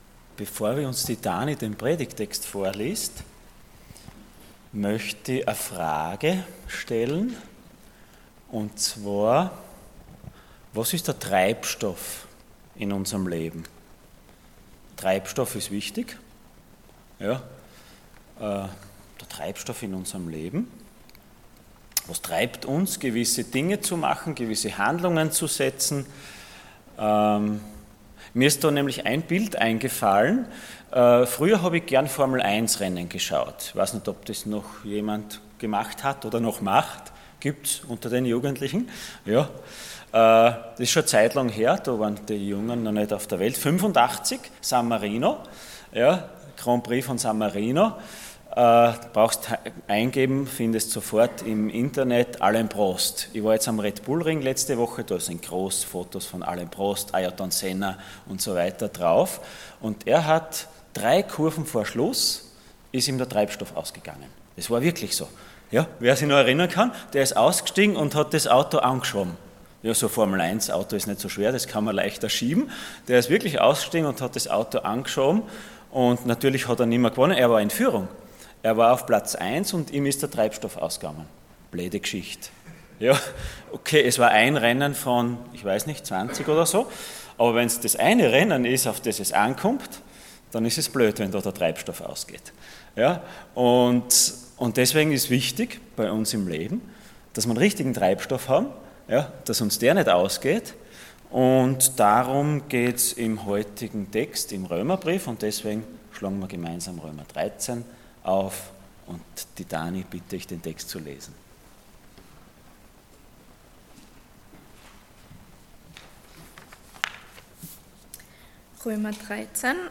Passage: Römer 13,8-14 Dienstart: Sonntag Morgen